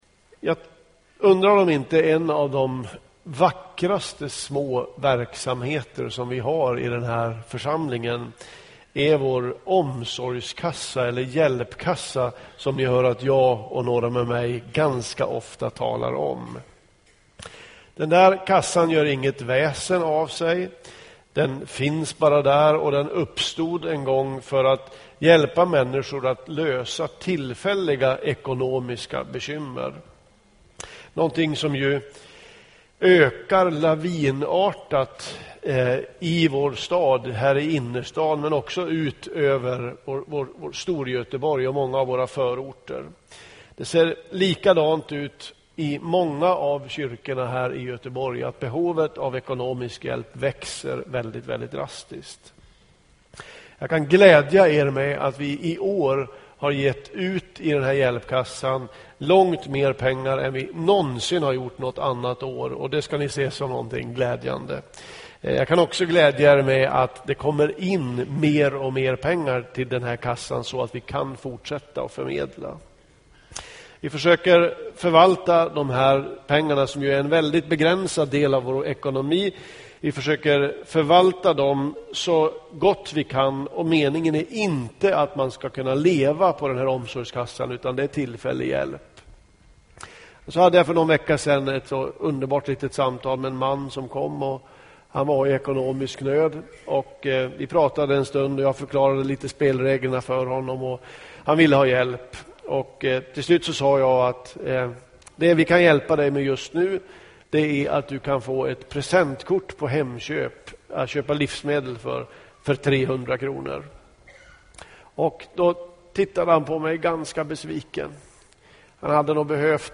Inspelad i Smyrnakyrkan, Göteborg 2012-10-21.